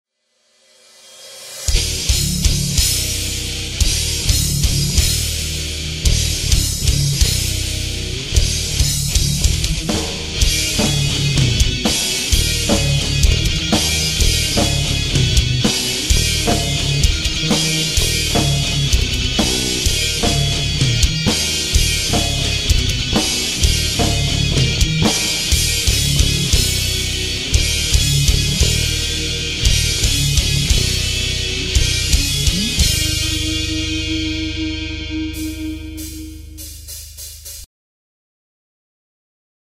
Metalcore
Gitarre
Schlagzeug
Bass und Gesang